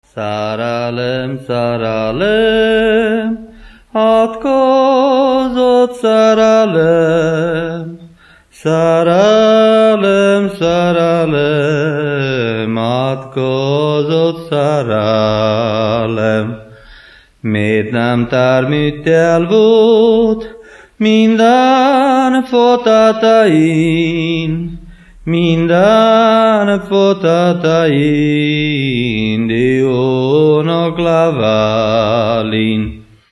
Moldva és Bukovina - Moldva - Trunk
Műfaj: Keserves
Stílus: 9. Emelkedő nagyambitusú dallamok
Szótagszám: 6.6.6.6